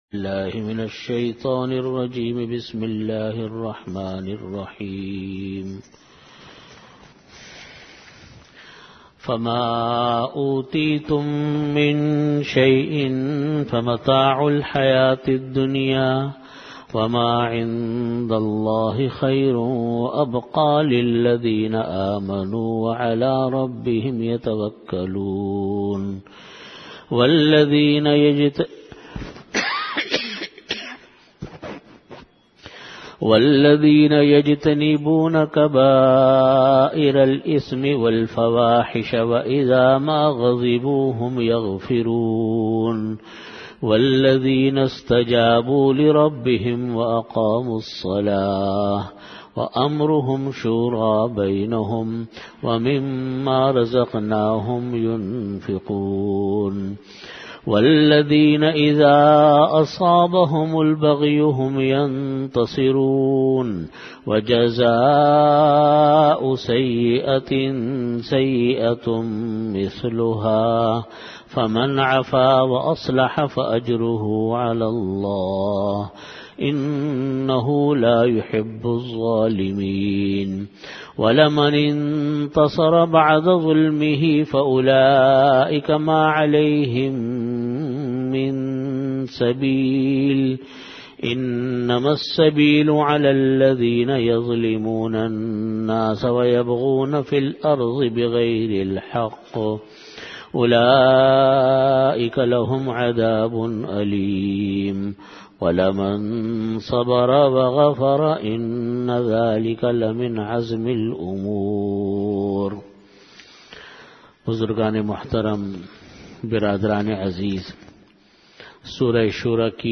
Tafseer · Jamia Masjid Bait-ul-Mukkaram, Karachi